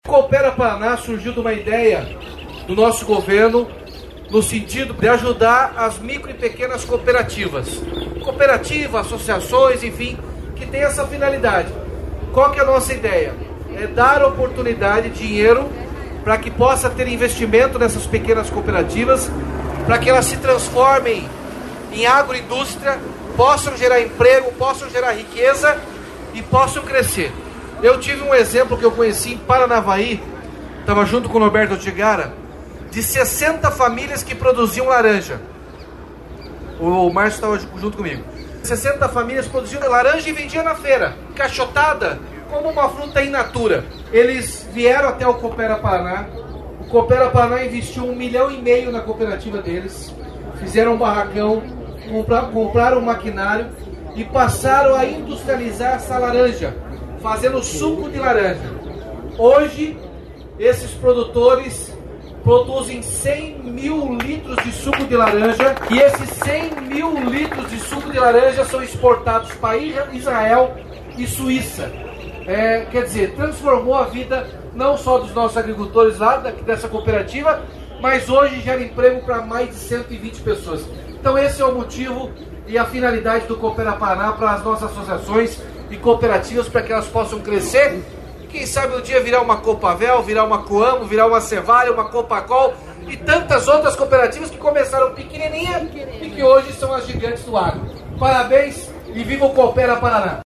Sonora do governador Ratinho Junior sobre a liberação de novos convênios para pequenas cooperativas